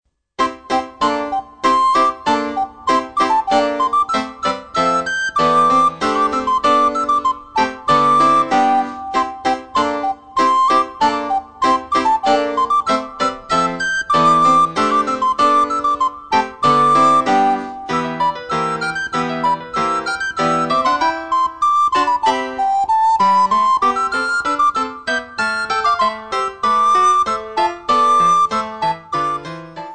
für Sopranblockflöte und Klavier
Besetzung: Sopranblockflöte und Klavier
Klassisches Blockflötenrepertoire auf Noten mit Playback-CD.